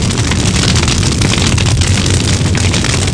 1 channel
FIRE1.mp3